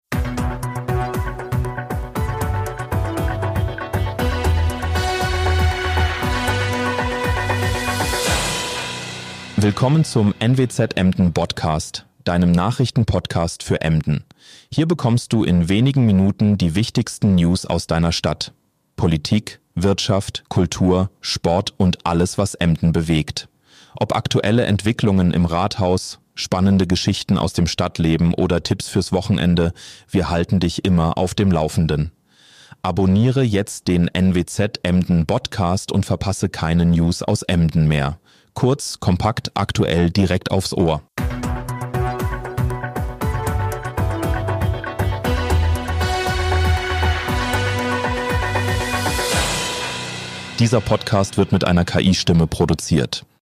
Dieser Podcast wird mit einer KI-Stimme